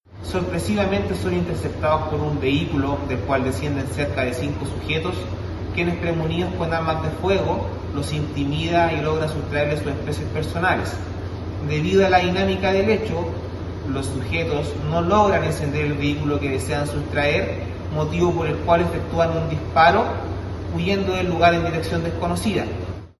Según información entregada por la Policía de Investigaciones, la situación ocurrió en la intersección de Isabel Montt con Bicentenario.